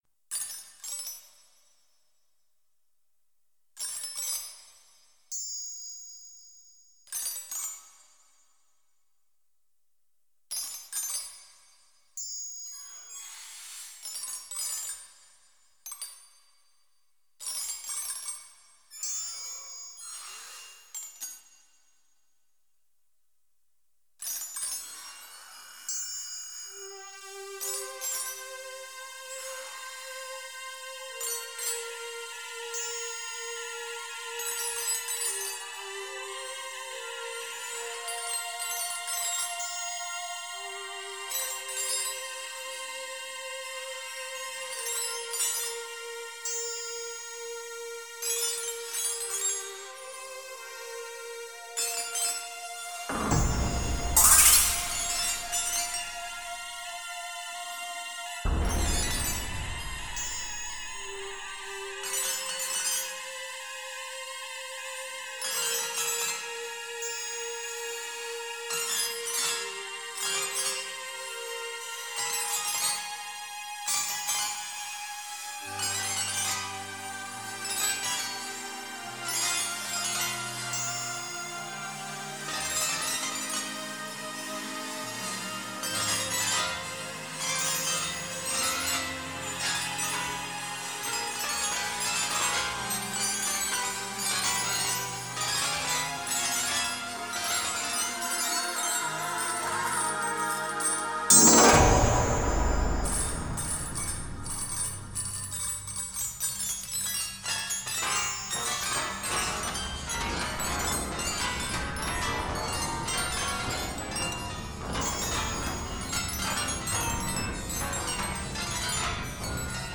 for Electronic music, Video, Laser, Performance and more
現在の音楽制作現場で行われている様な、DAWや、パソコン上で動くソフトウェアシンセサイザーでは無く、複数のシンセサイザー等をMIDIで繋ぎ、専用のシーケンサで制作する方法が主流で、シーケンサ上ではオーディオを扱っていない時代です。
なお、残っていた音源がプラネタリウム公演用で、ダイナミクスの幅が広かったものは、若干のコンプ処理をしてあります。